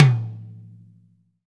TOM TOM105.wav